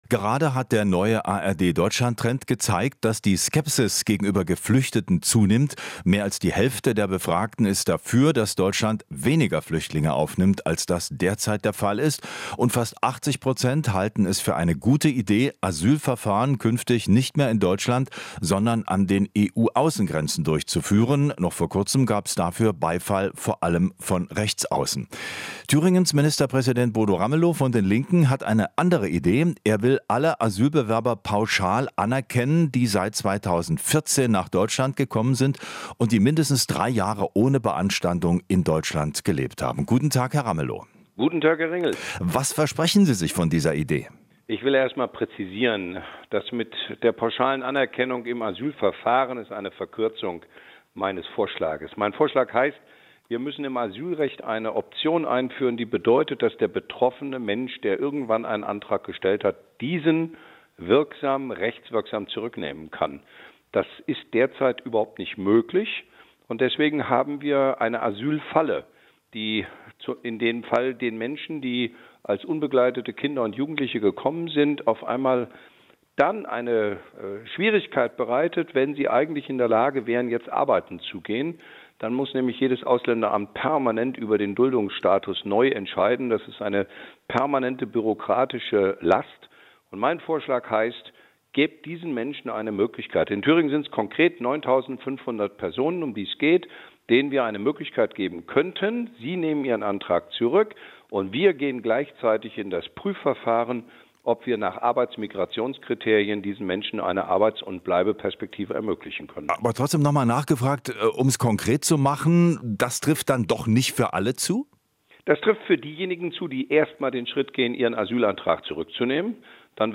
Interview - Ramelow (Linke): Wir haben eine "Asylfalle"